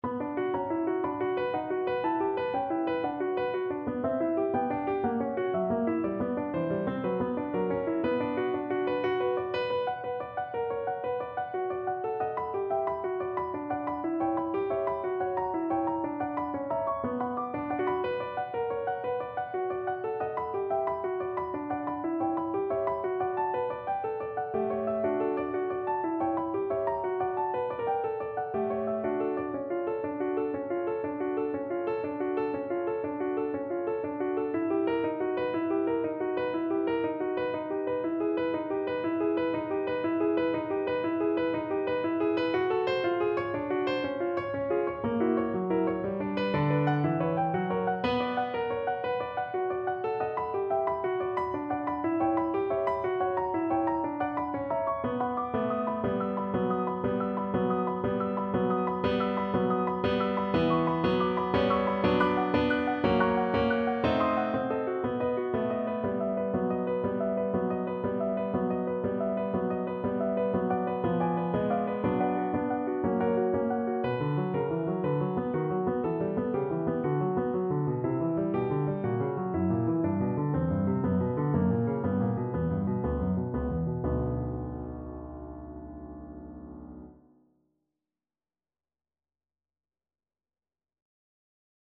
Cello version
2/4 (View more 2/4 Music)
= 38 Etwas langsam
Classical (View more Classical Cello Music)